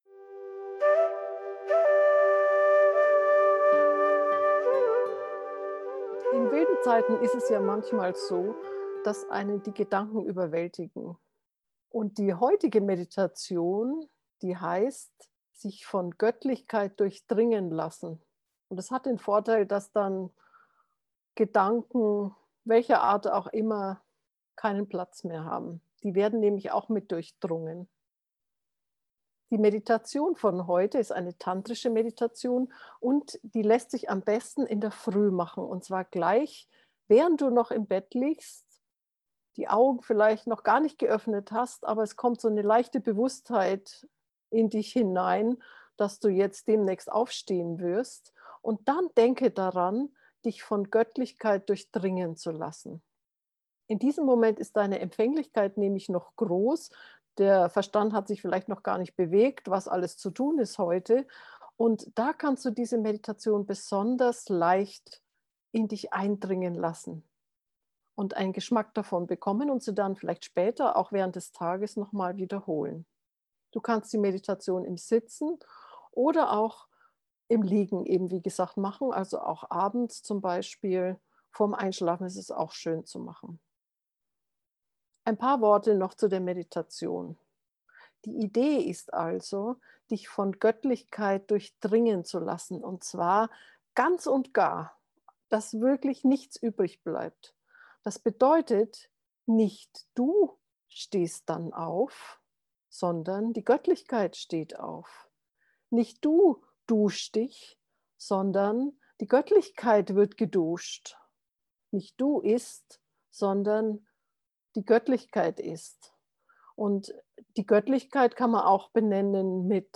morgenmeditation-goettlichkeit-gefuehrte-meditation